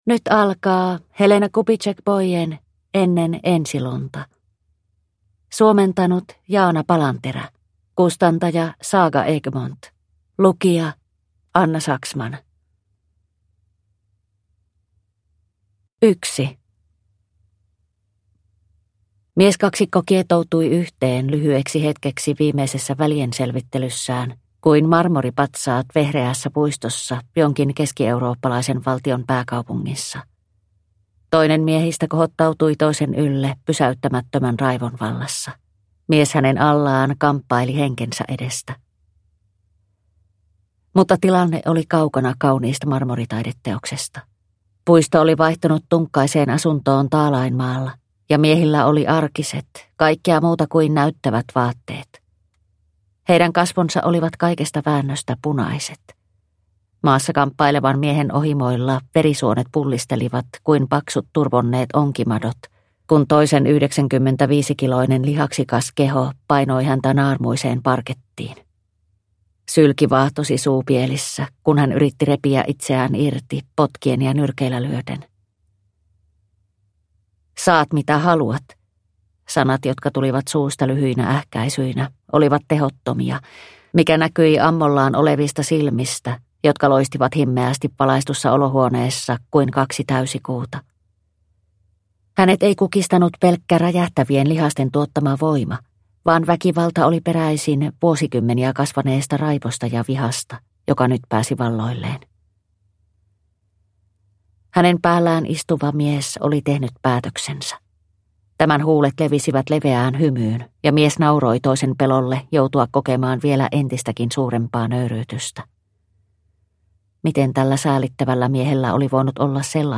Ennen ensilunta / Ljudbok